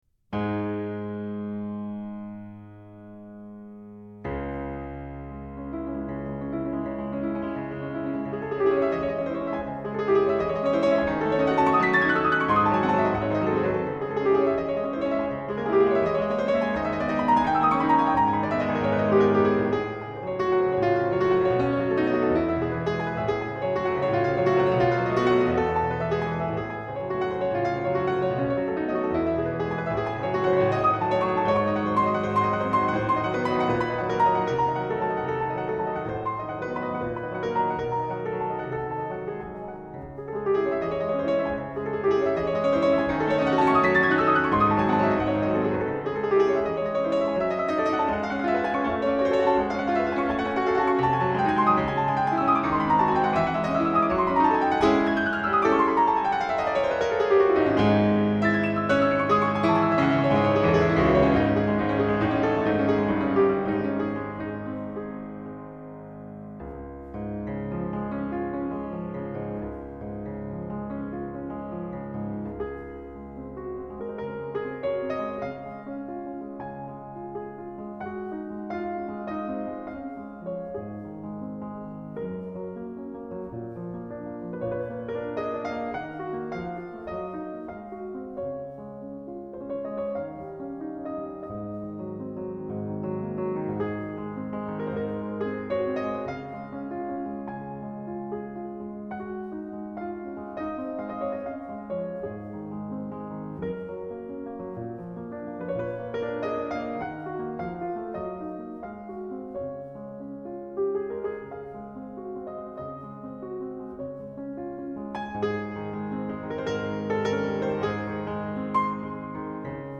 音色细腻迷人,节奏自然而变化丰富 触键冷静，扎实。